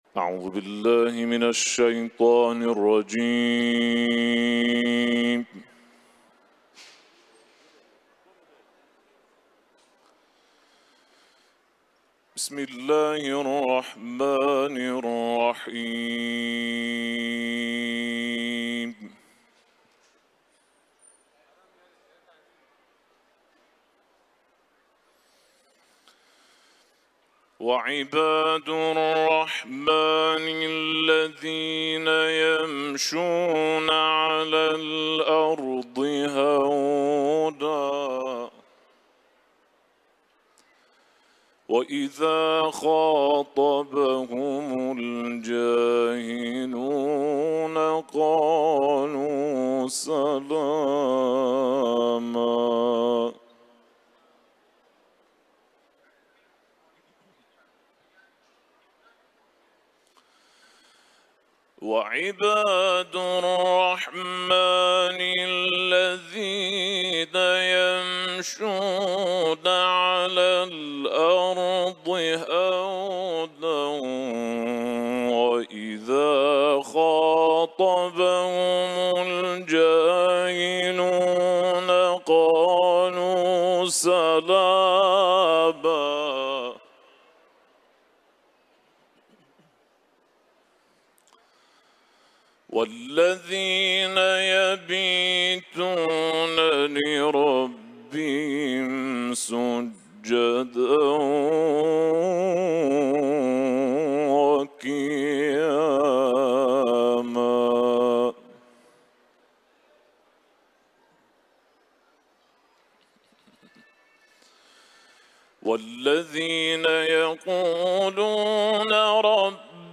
Etiketler: İranlı kâri ، Kuran tilaveti ، Furkan suresi